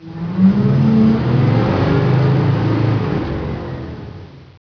1 channel
TRUCK.WAV